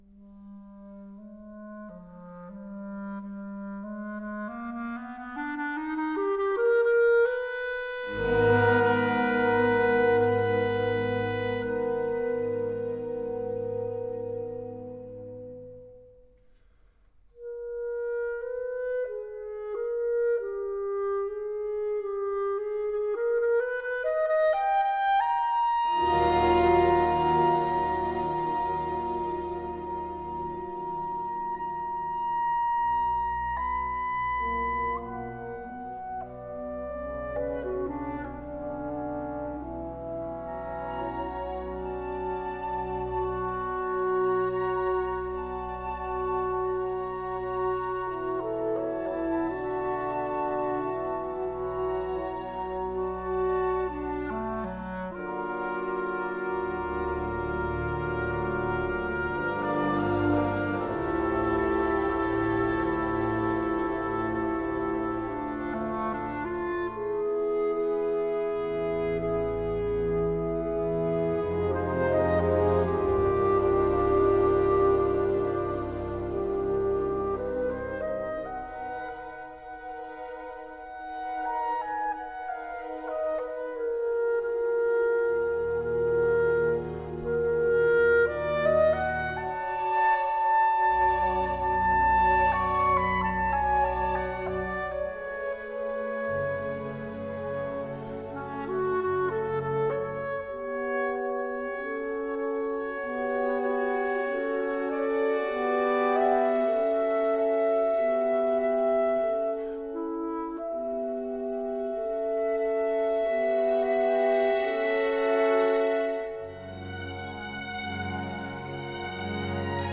For Clarinet and Chamber Orchestra